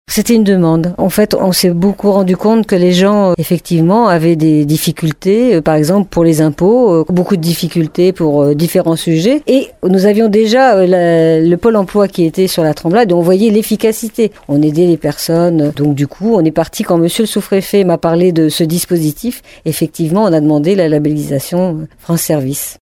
Inauguration de l’espace France services ce matin à La Tremblade.
Un espace qui vient répondre à un besoin de la population, comme le précise la maire de La Tremblade Laurence Osta-Amigo :